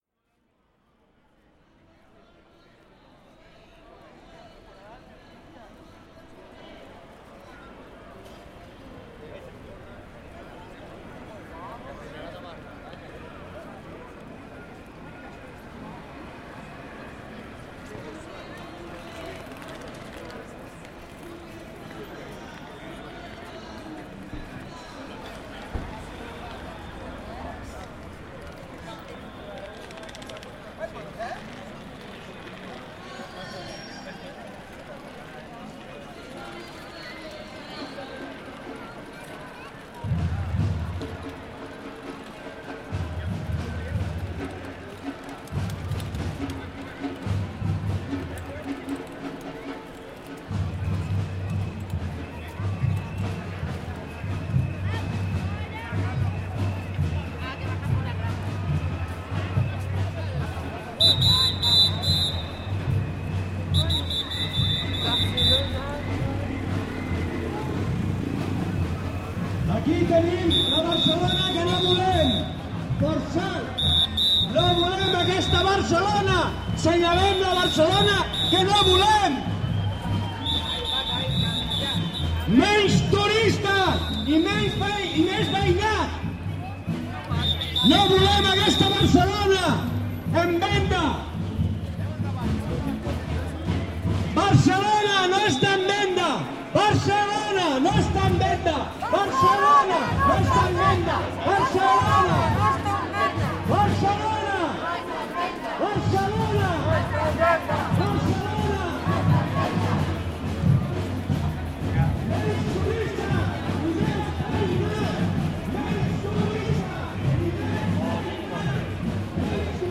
Demonstration against touristification of the neighbourhood.
People come down from right side and pass to the left side. Someone points out one building acquired by a big company for speculation while the neighbours and local shopkeepers are driven out from theirs homes and shops because of the rising of rental prices. More people pass down backed by a group of percussionists. Some petards explodes in the distance and mix with slogans, whistles, coming and passed percussions, and, obviously, many tourists.